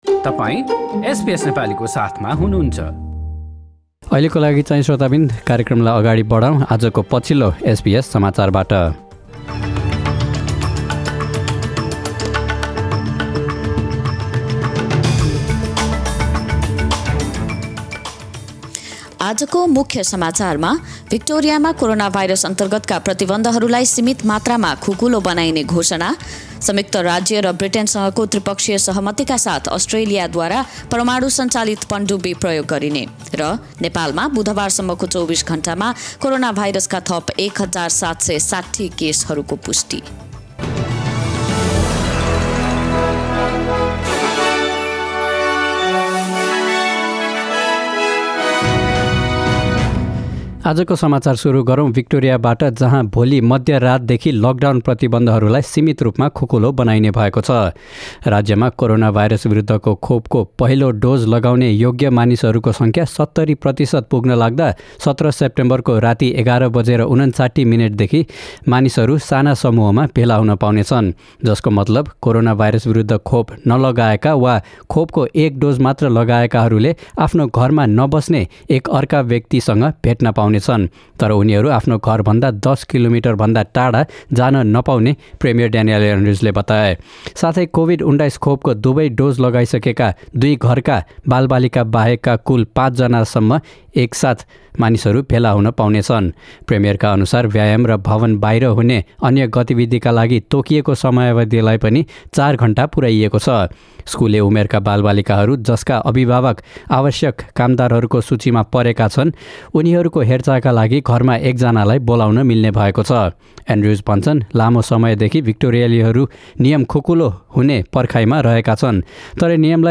SBS Nepali Australia News: Thursday 16 September 2021